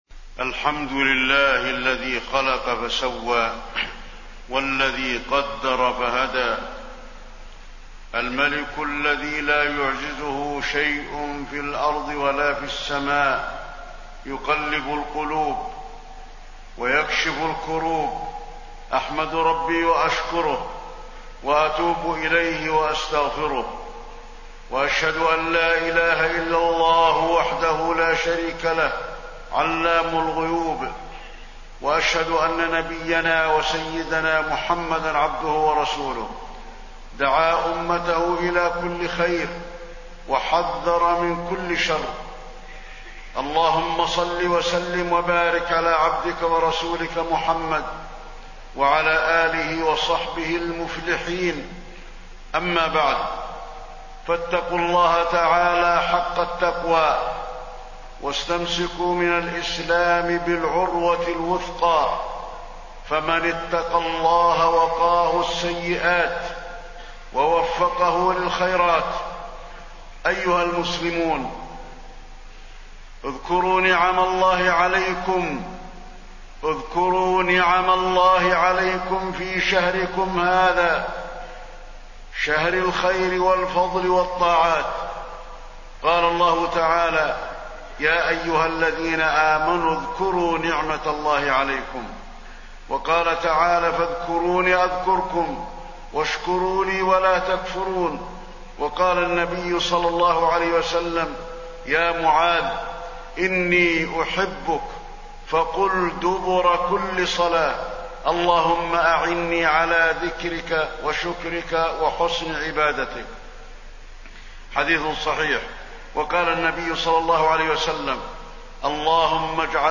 تاريخ النشر ٢٦ رمضان ١٤٣٢ هـ المكان: المسجد النبوي الشيخ: فضيلة الشيخ د. علي بن عبدالرحمن الحذيفي فضيلة الشيخ د. علي بن عبدالرحمن الحذيفي نعيم رمضان ولذة الأعمال الصالحة